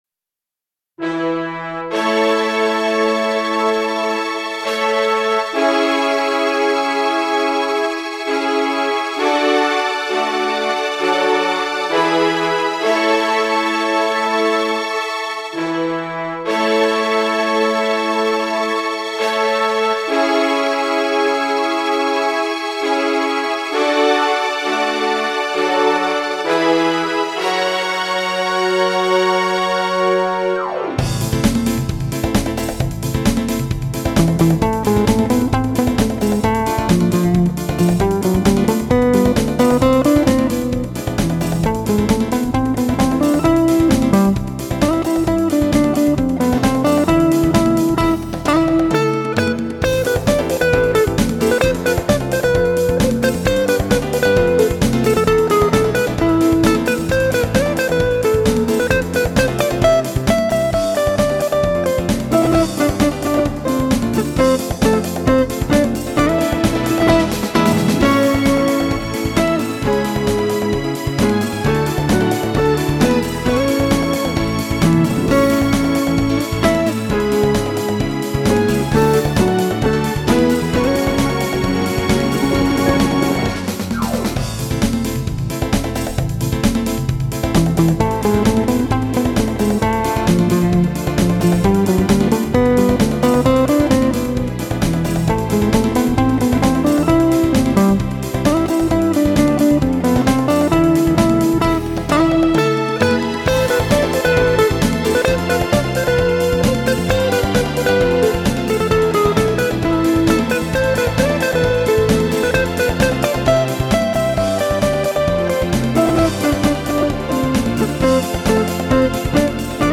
Слушать или скачать минус к песне